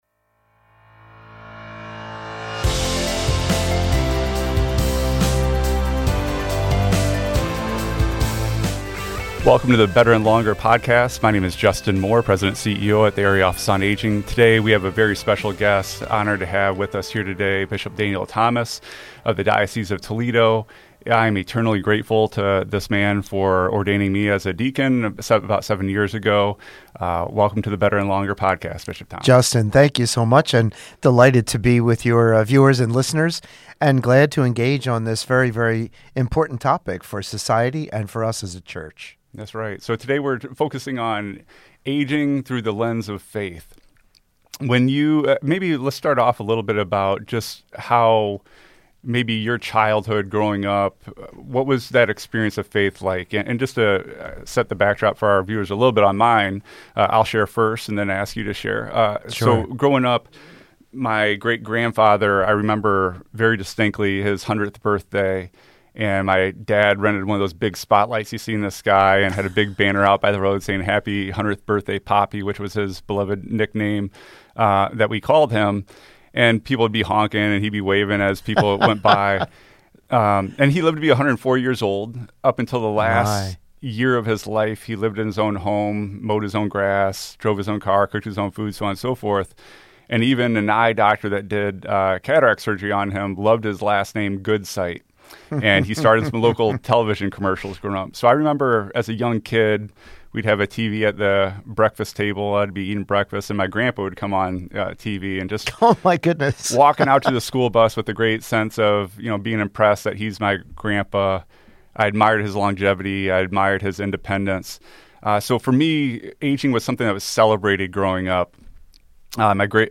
A Conversation with Bishop Daniel E. Thomas - WGTE Public Media